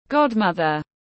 Mẹ đỡ đầu tiếng anh gọi là godmother, phiên âm tiếng anh đọc là /ˈɡɑːdmʌðər/.
Godmother /ˈɡɑːdmʌðər/